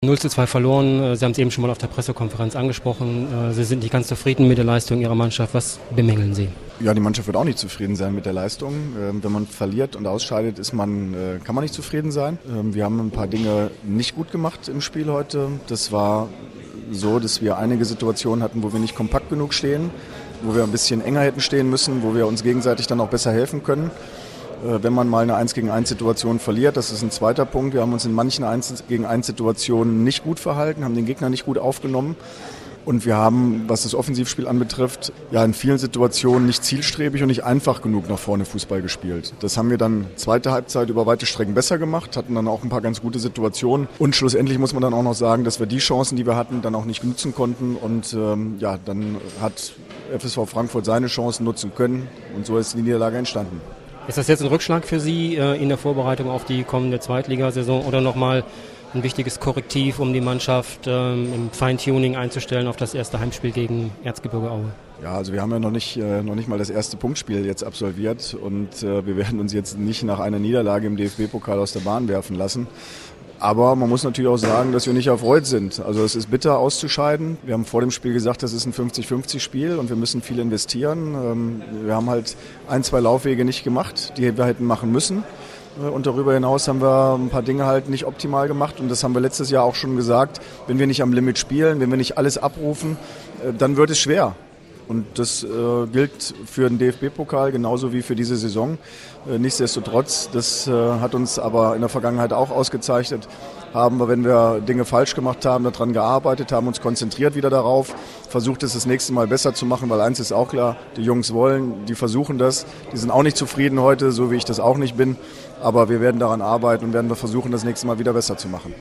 AUDIOKOMMENTAR